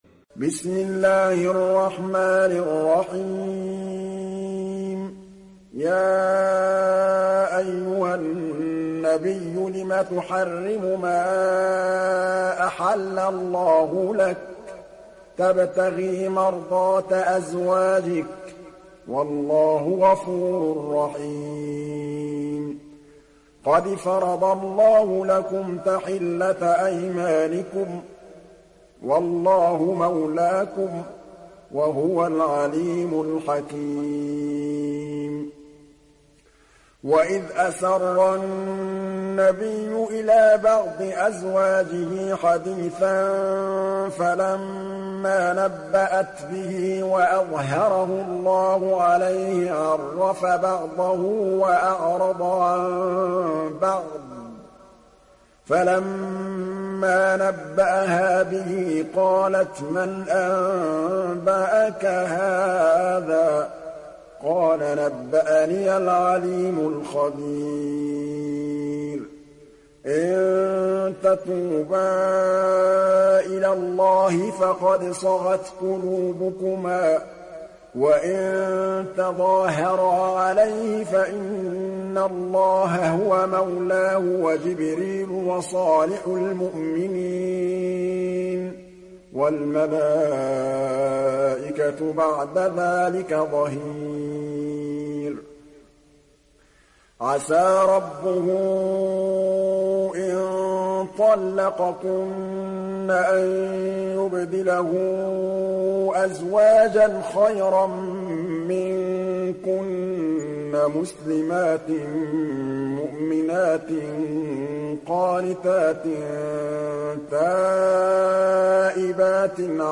دانلود سوره التحريم mp3 محمد محمود الطبلاوي روایت حفص از عاصم, قرآن را دانلود کنید و گوش کن mp3 ، لینک مستقیم کامل